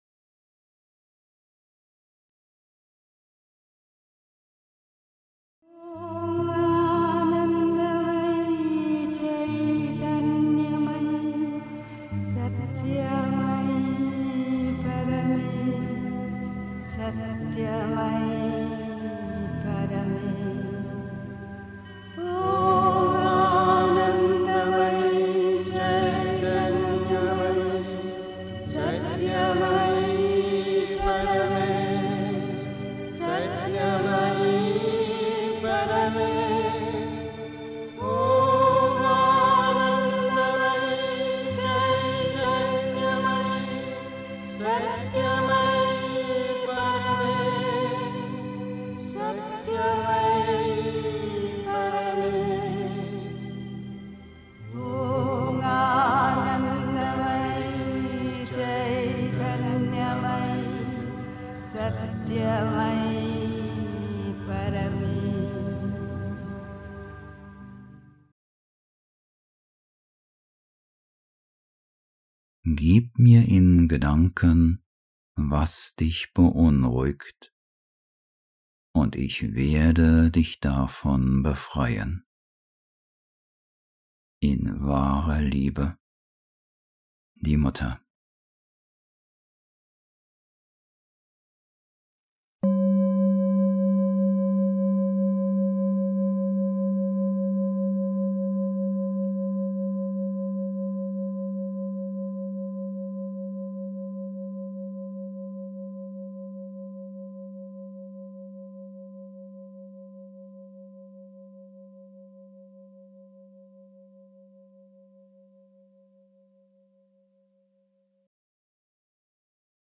1. Einstimmung mit Musik. 2. Ich werde dich davon befreien (Die Mutter, White Roses, 28 January 1963) 3. Zwölf Minuten Stille.